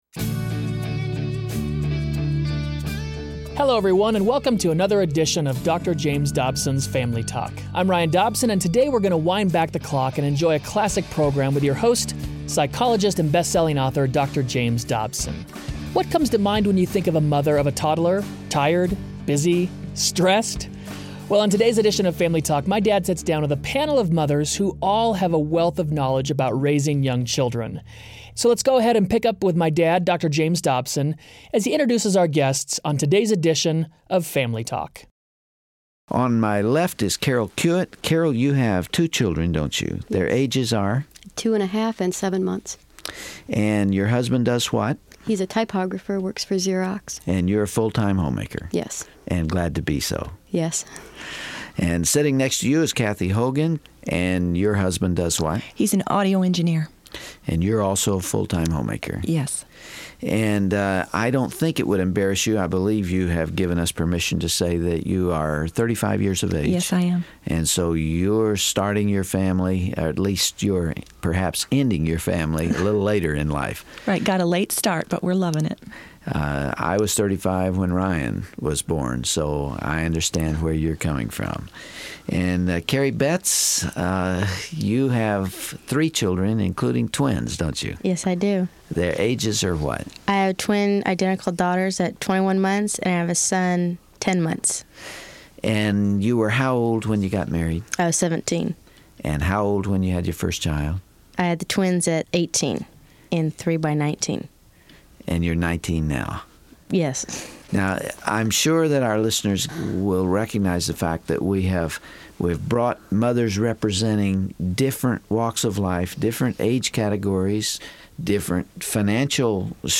On the next edition of Family Talk, Dr. James Dobson sits down with a panel of mothers who share a wealth of knowledge about raising young children.